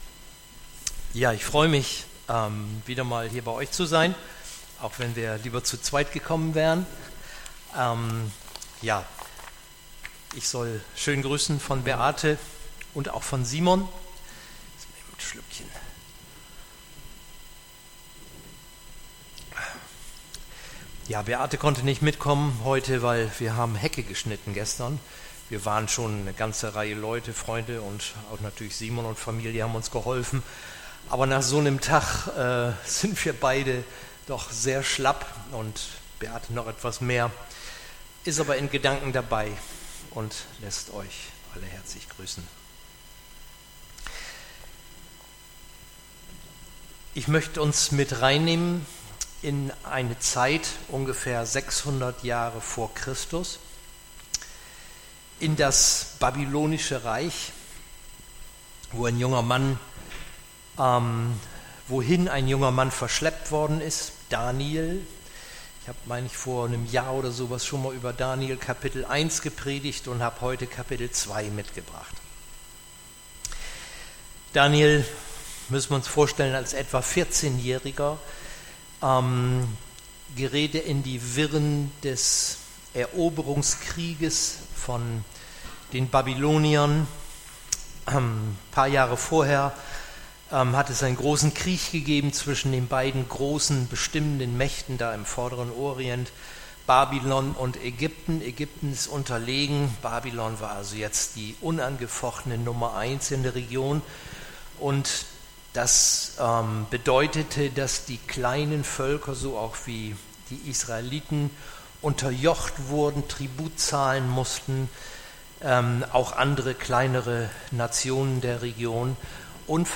Aktuelle Predigten der Markusgemeinde Emsdetten; Wir feiern jeden Sonntag in der Martin-Luther-Kirche in Emsdetten (Neubrückenstrasse 96) unseren Gottesdienst.